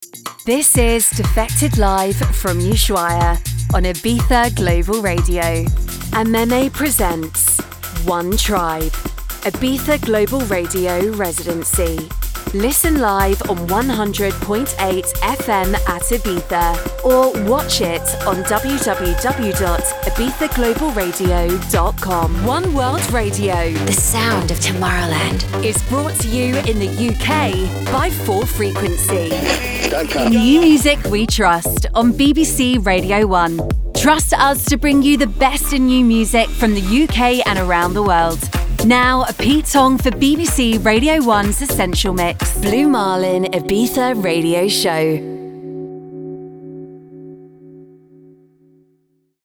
Imagens de rádio
Sennheiser MK4
Jovem adulto
Mezzo-soprano